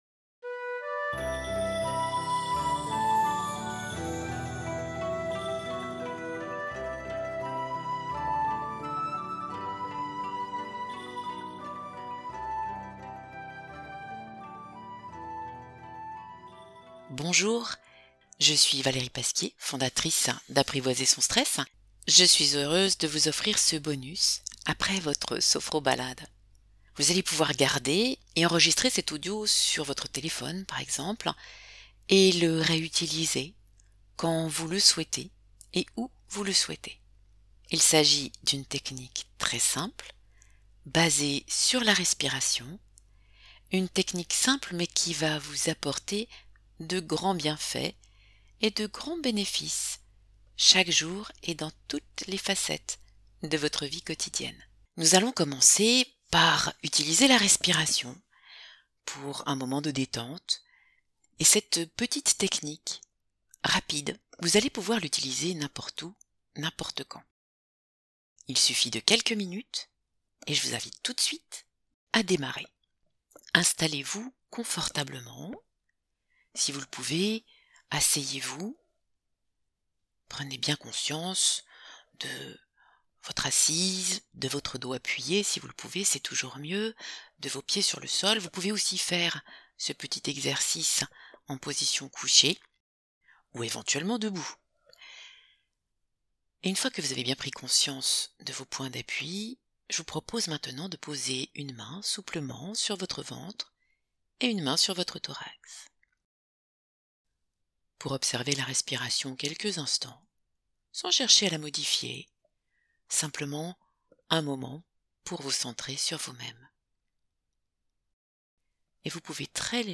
Genre : Speech.